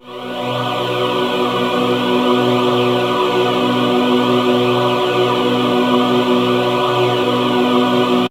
VOX_CHORAL_0001.wav